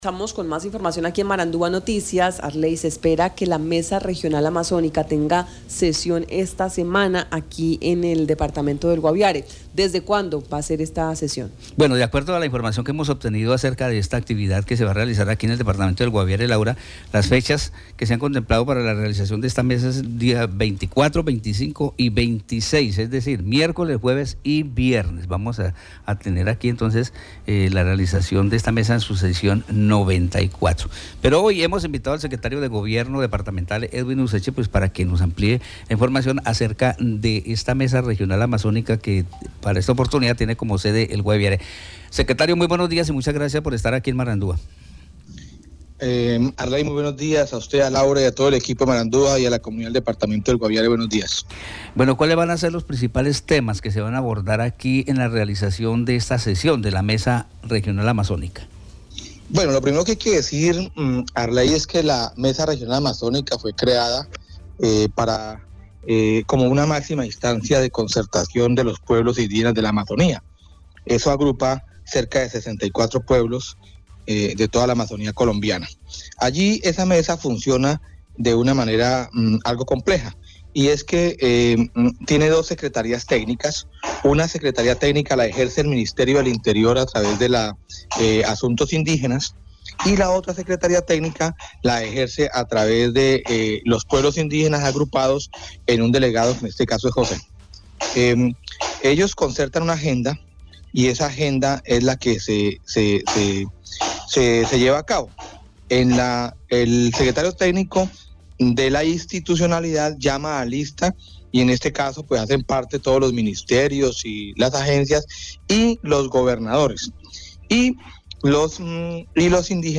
El secretario de Gobierno Departamental, Edwin Useche Niño, explicó que esta mesa se creó como la máxima instancia para conocer los avances y realizar el seguimiento a los compromisos adquiridos por las entidades nacionales y territoriales.